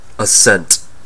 Ääntäminen
IPA : /ə.ˈsɛnt/